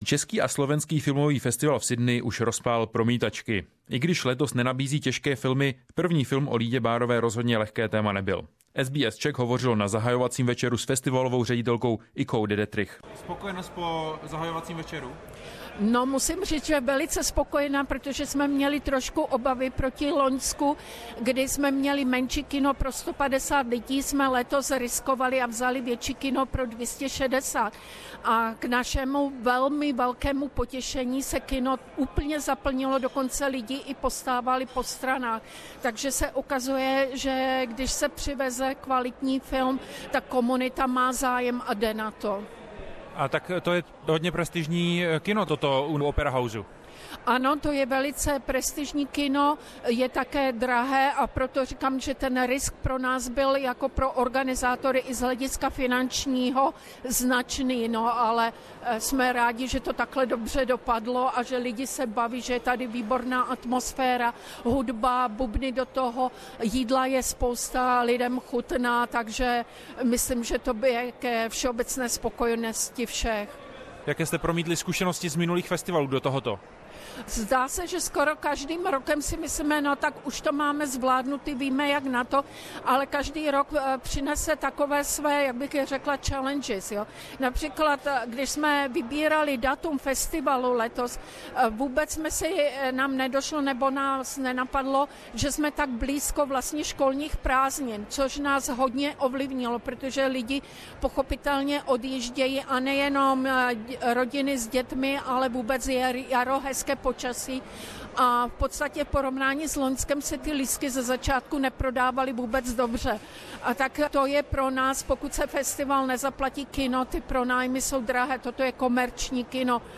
SBS Czech hovořilo na zahajovacím večeru